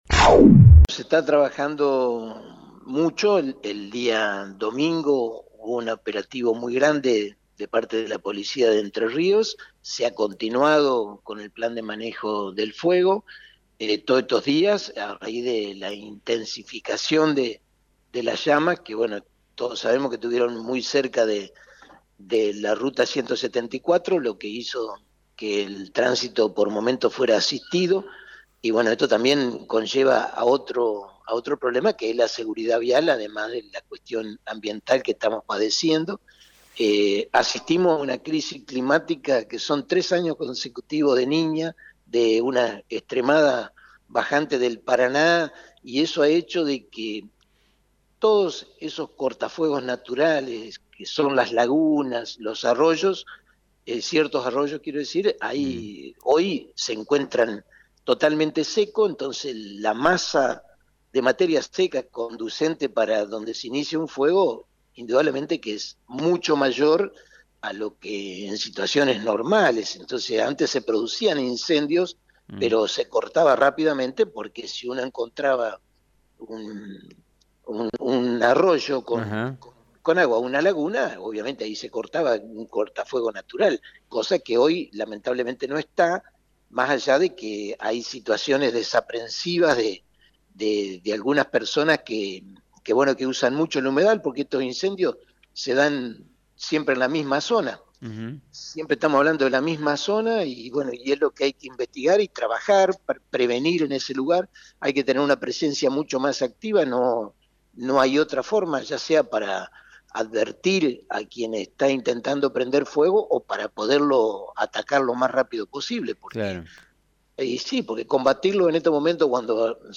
En diálogo con FM 90.3, el intendente denunció que los trabajos de dragado para garantizar el calado de los buques que ingresan a San Lorenzo y Rosario no ha tenido en cuenta dónde depositar el material extraído dificultando el ingreso de agua al humedal.